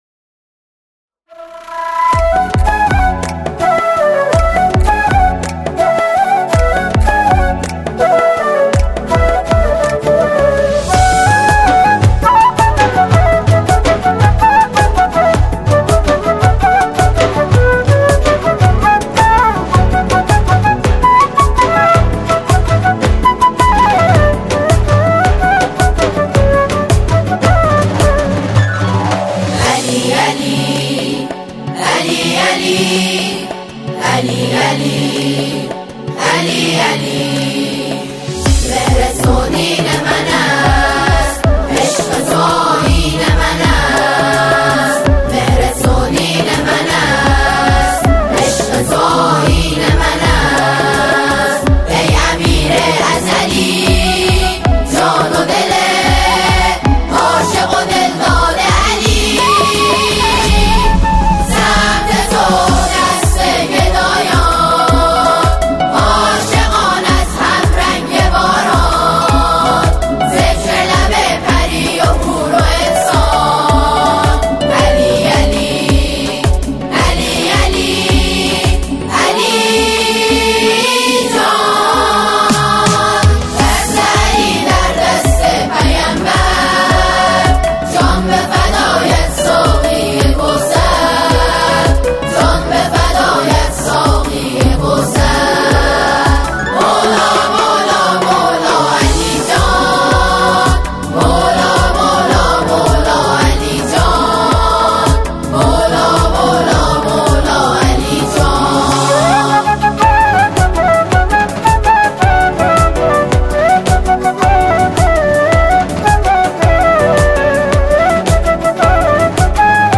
با هم آوایی نوجوانان است.